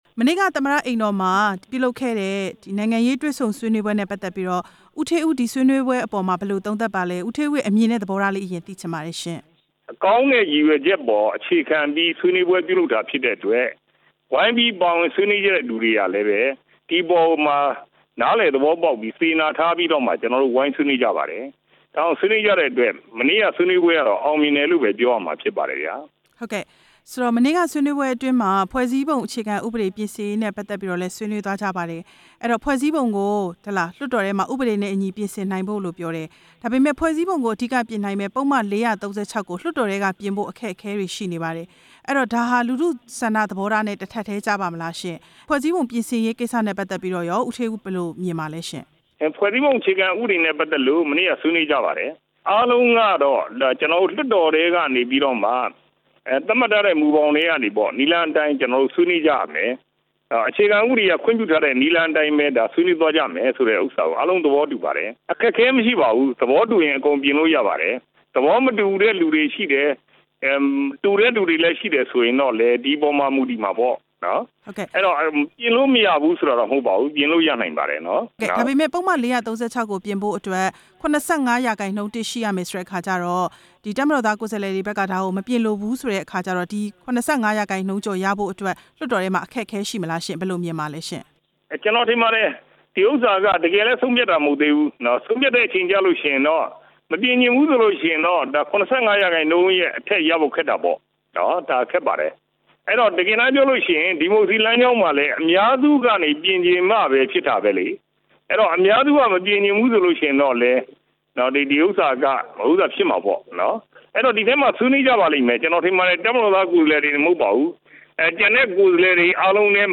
နေပြည်တော်က ထိပ်သီး ၁၄ ဦး ဆွေးနွေးပွဲ ဦးဌေးဦးနဲ့ မေးမြန်းချက်